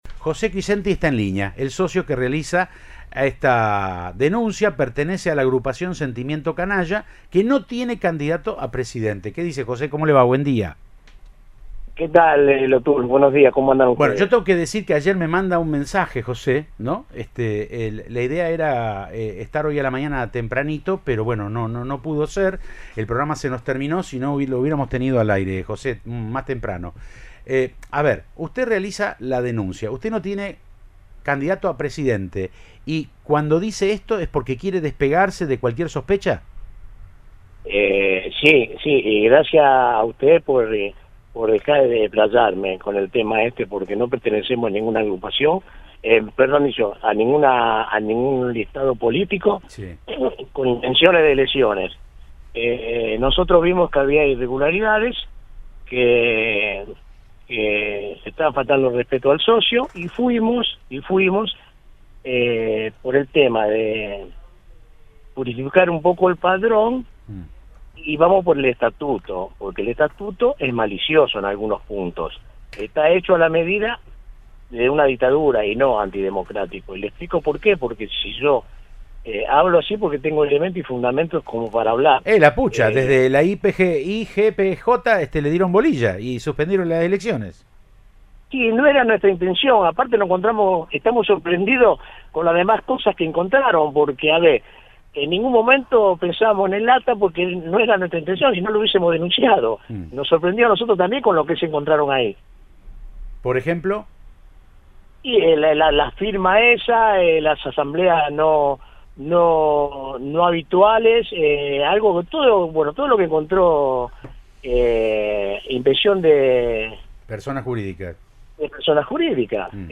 es el asociado del club que en diálogo con Siempre Juntos de Cadena 3 Rosario resaltó que pertenece a “una agrupación sin candidato a presidente”.